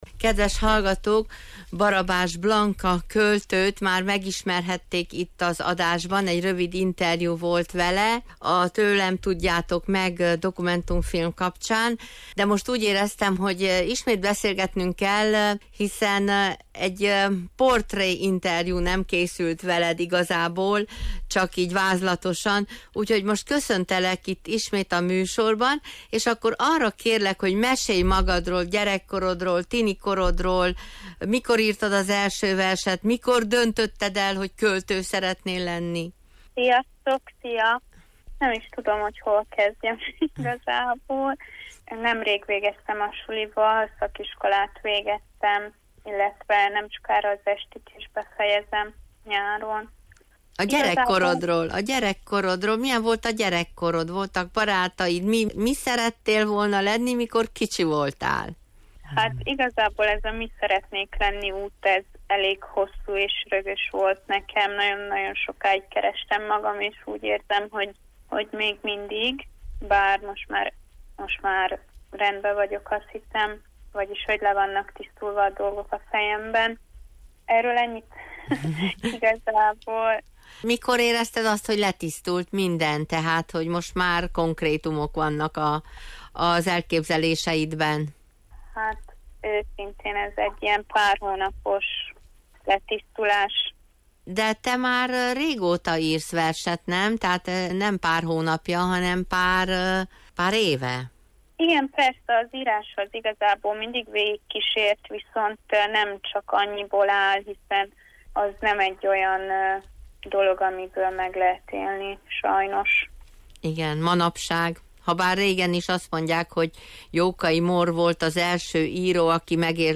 A beszélgetés végén a fiatal költő két versét is felolvas nekünk.